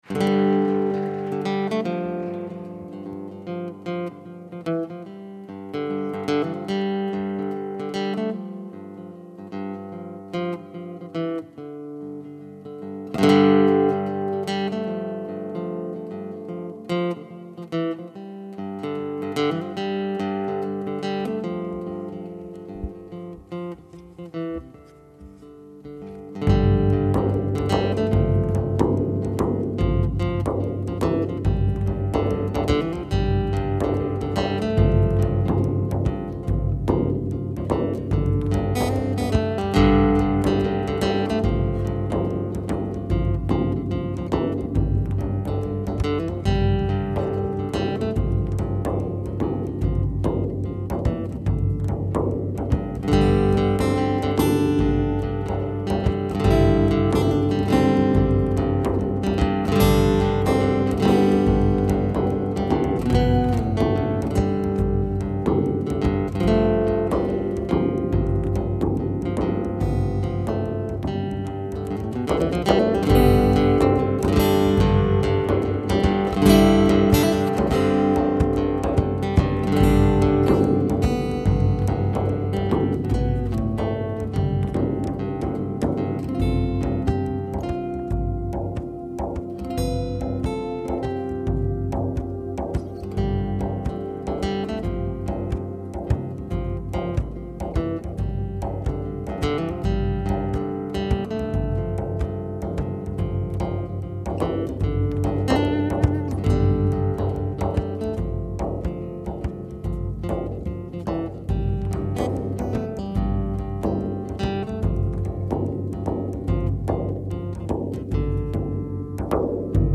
Live at the Syracuse Center for the Arts 2/9/08
Sometimes the simplest melodies are the most catchy.